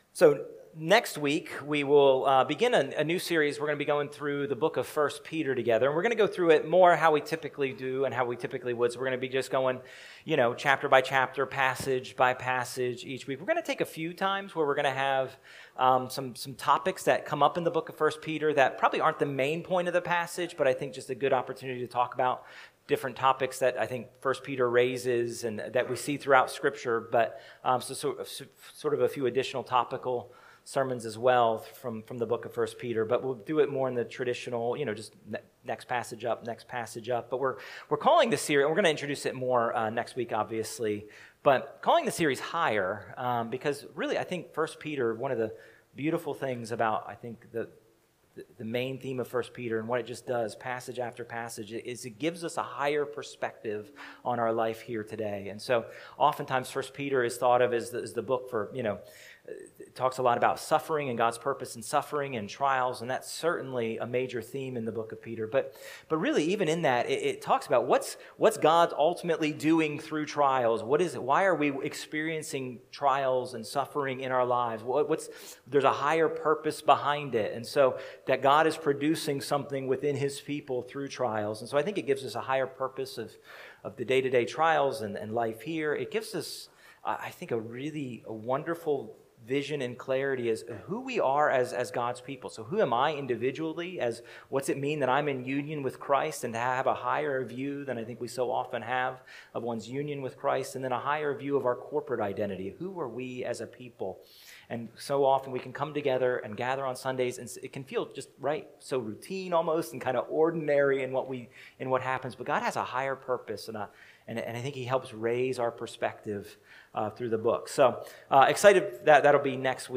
A message from the series "God Pities The Fool." In Proverbs 1:8-19 we see the good gift of godly parents. In our conclusion of the passage we see that in order to be wise, the young must listen to their parents instruction. We see the danger in other voices and the life that comes from leaning into Mom and Dad.